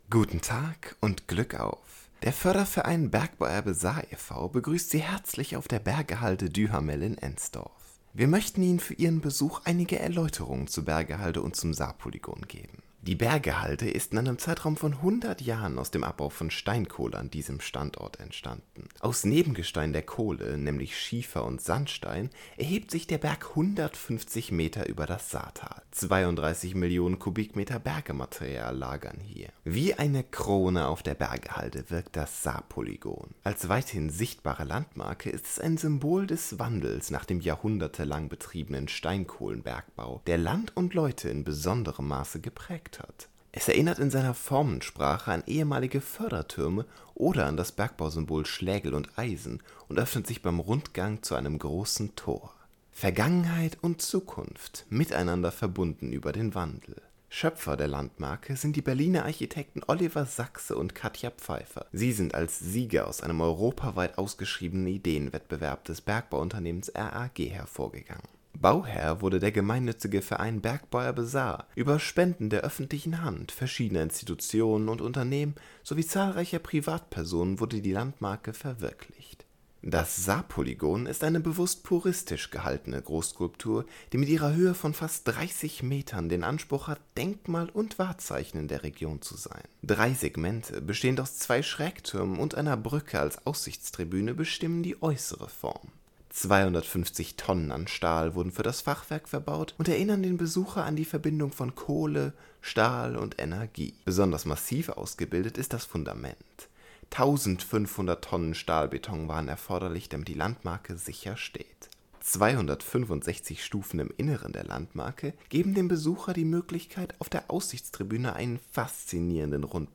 Audioguide dt.
D_Leitfaden-Saarpolygon-Deutsche-Variante.mp3